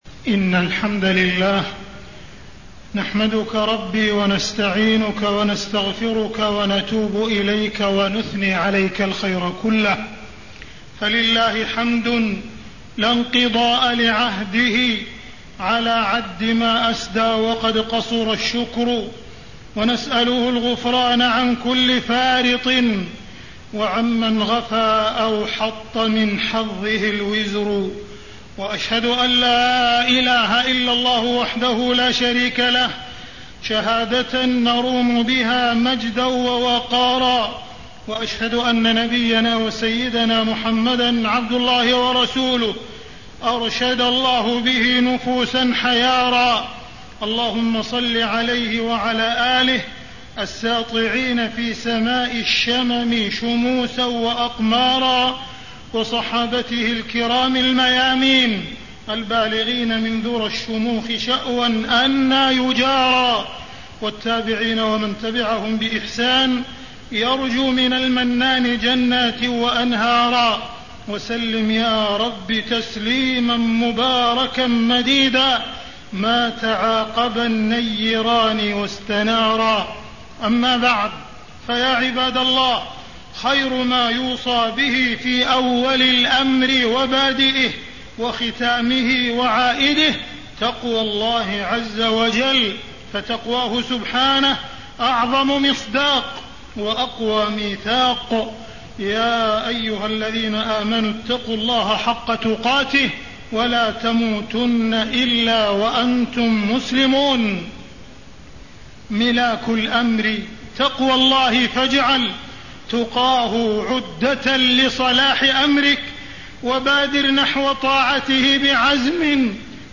تاريخ النشر ٢ ربيع الأول ١٤٣٥ هـ المكان: المسجد الحرام الشيخ: معالي الشيخ أ.د. عبدالرحمن بن عبدالعزيز السديس معالي الشيخ أ.د. عبدالرحمن بن عبدالعزيز السديس سفينة النجاة اتباع الوحيين